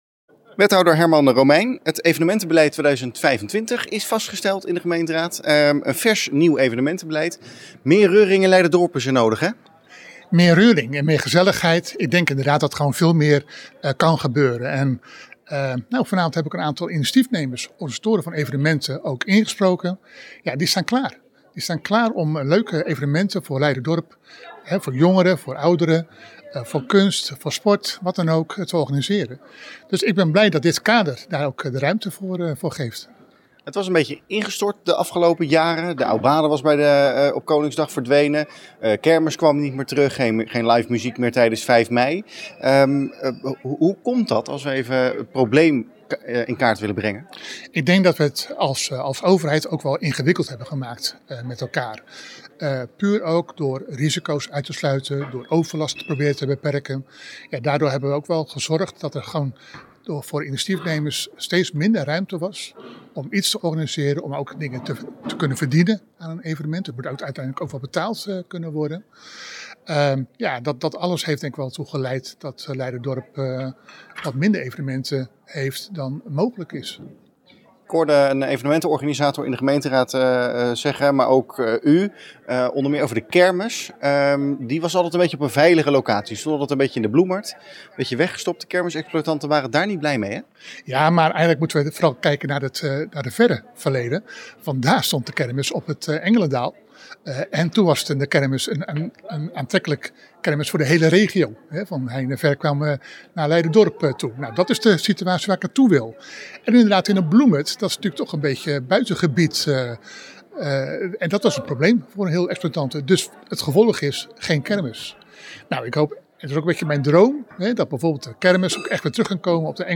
Wethouder Herman Romeijn over het nieuwe evenementenbeleid.
Herman-Romeijn-over-evenementen.mp3